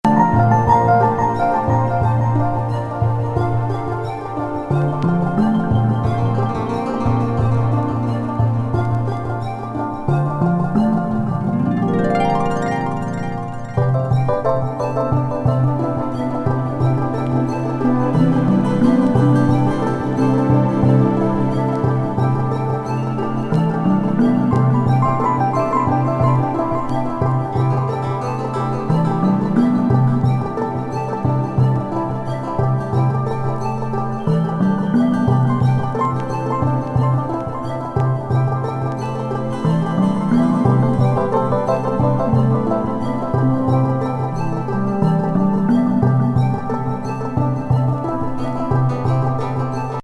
フワトロ天界ニューエイジ「海底の中の